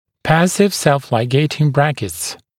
[‘pæsɪv self-laɪˈgeɪtɪŋ ‘brækɪts][‘пэсив сэлф-лайˈгейтин ‘брэкитс]]пассивные самолигирующиеся брекеты